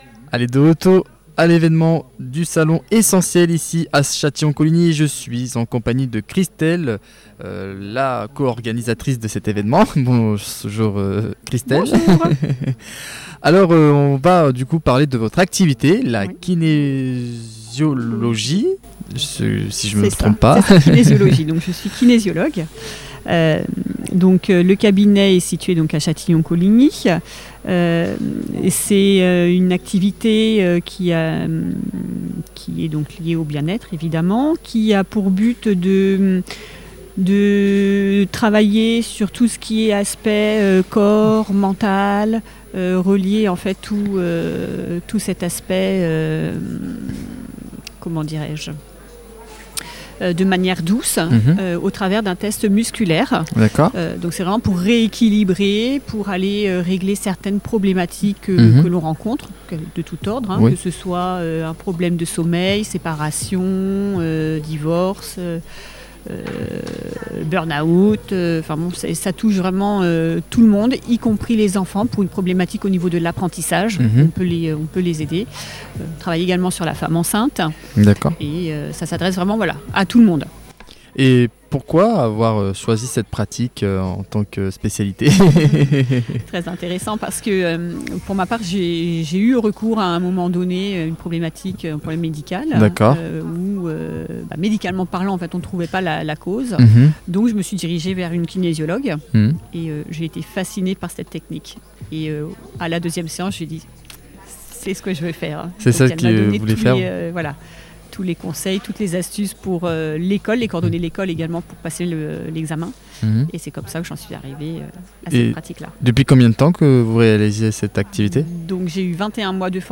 Les interviews du Salon Essenti’Elles – Édition 2026
À l’occasion du Salon Essenti’Elles, organisé les 7 et 8 mars 2026 au gymnase de Châtillon-Coligny, l’équipe de Studio 45 est allée à la rencontre des organisatrices et des nombreux exposants présents durant ce week-end consacré au bien-être et à l’univers féminin.